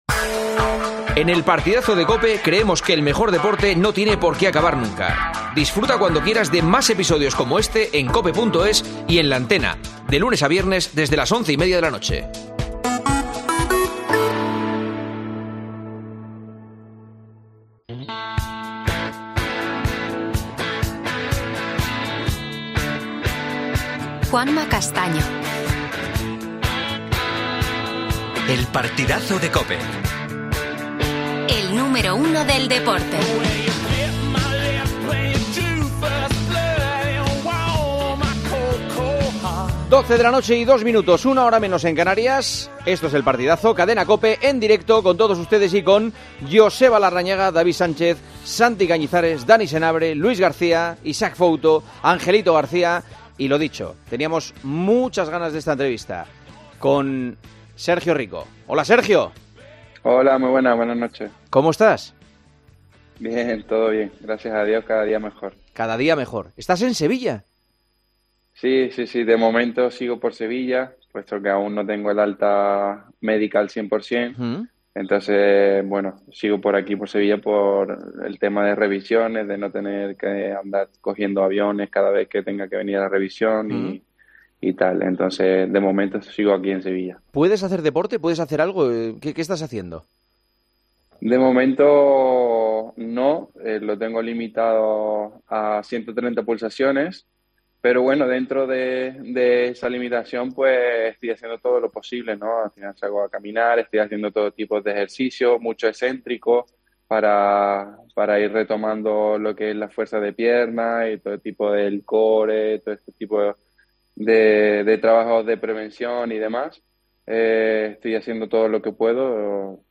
Escucha la charla entre Juanma Castaño y el futbolista del PSG.